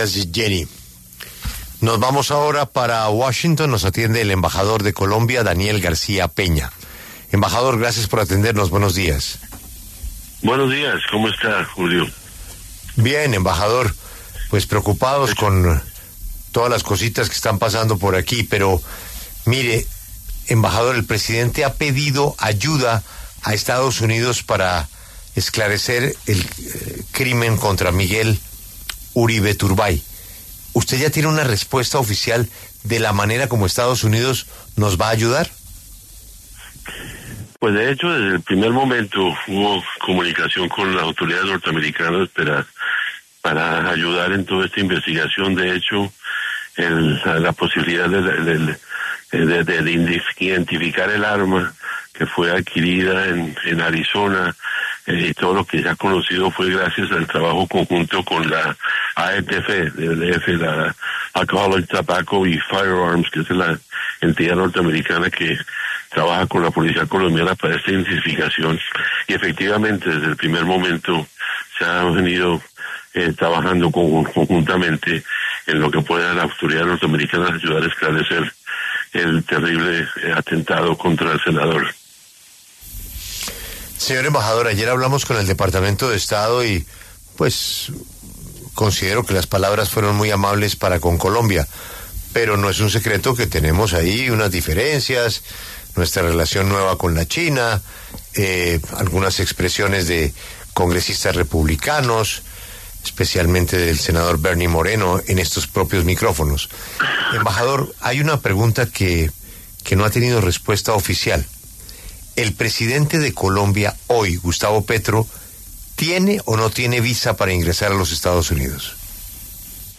El embajador Daniel García Peña conversó en La W sobre la relación entre ambos países, el apoyo que el gobierno estadounidense ofreció para el caso Miguel Uribe y la certificación en la lucha contra las drogas.